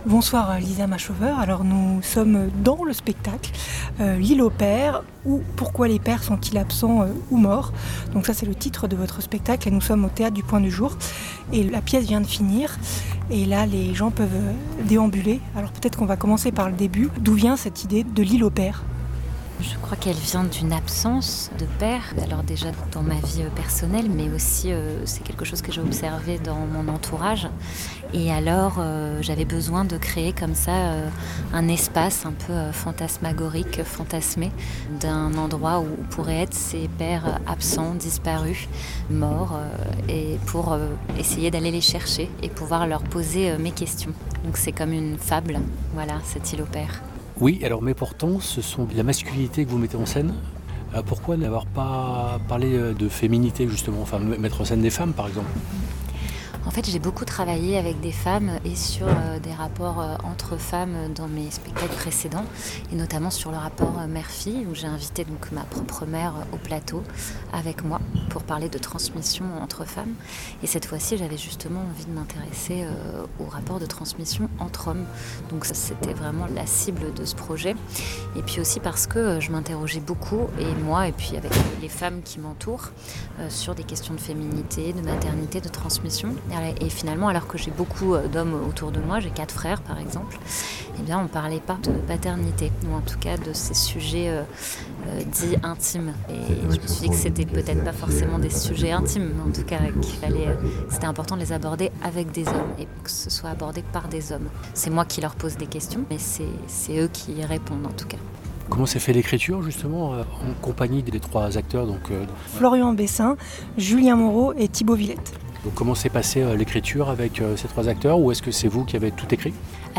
répond aux questions de Chœur pendant que le public déambule sur scène (9min 30)